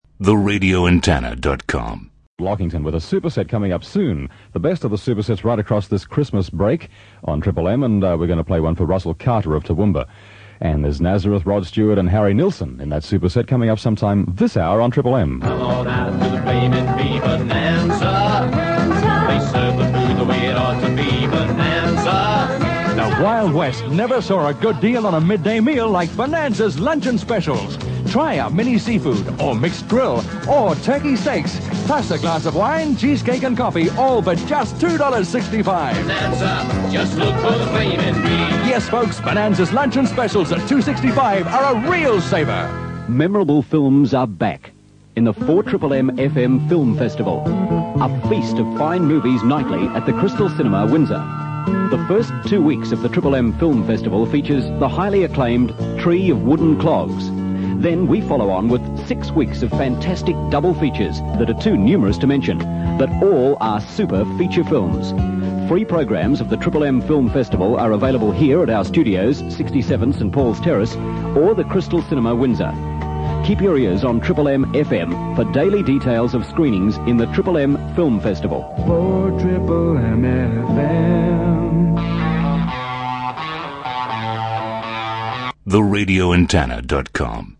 RA Aircheck